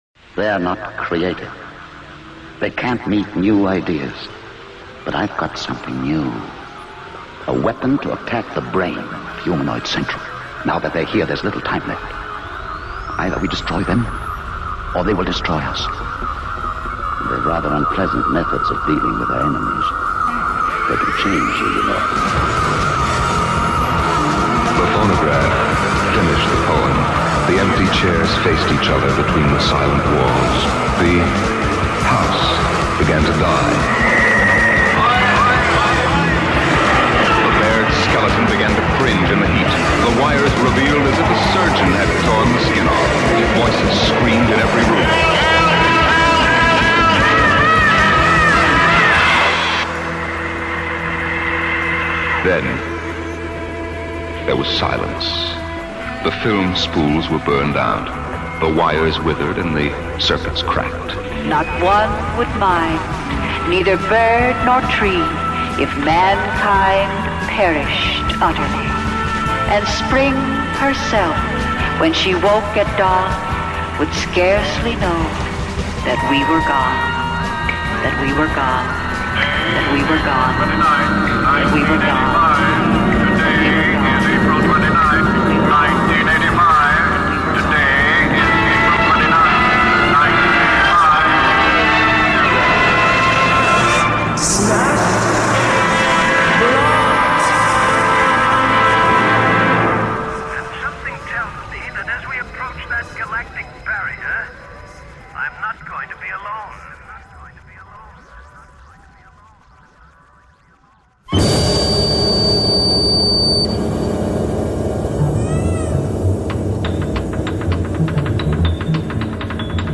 Jazz, punk, krautrock, psychedelia, noise, hardcore, folk, avant-garde & weird bleak rock & roll.